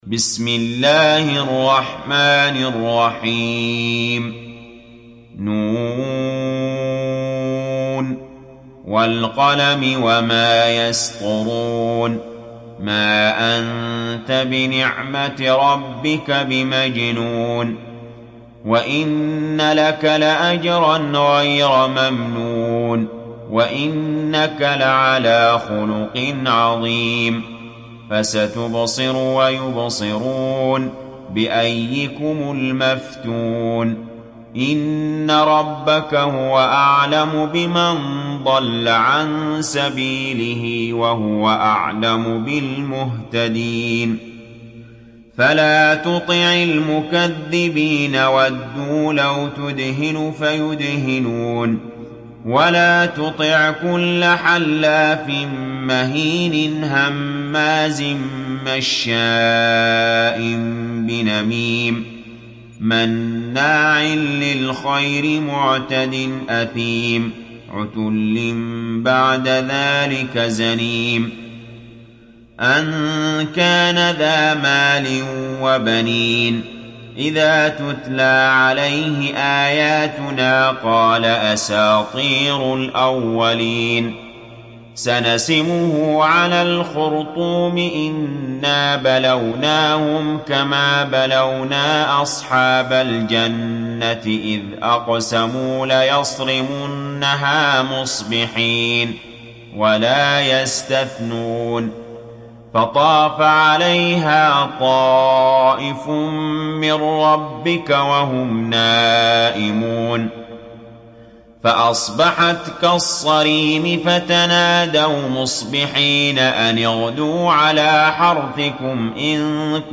تحميل سورة القلم mp3 بصوت علي جابر برواية حفص عن عاصم, تحميل استماع القرآن الكريم على الجوال mp3 كاملا بروابط مباشرة وسريعة